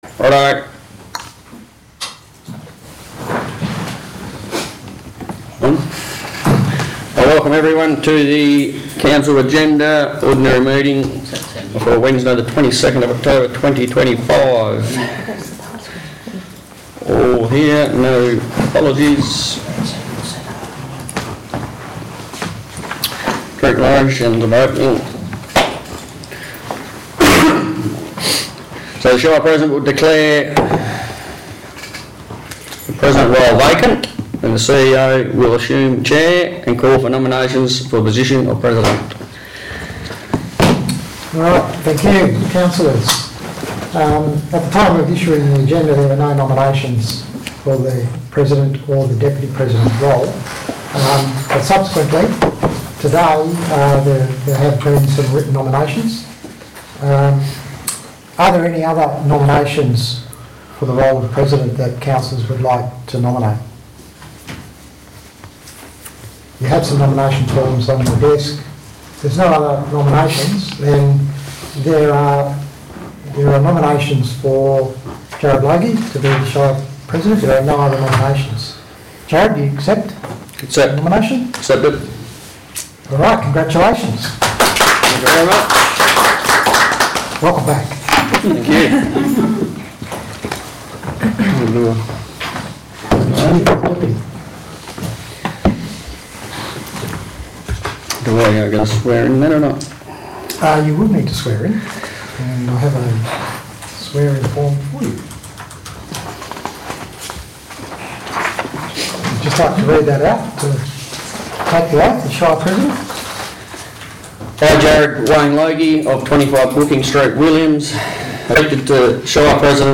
Ordinary Meeting of Council - Wednesday 22nd October 2025 » Shire of Williams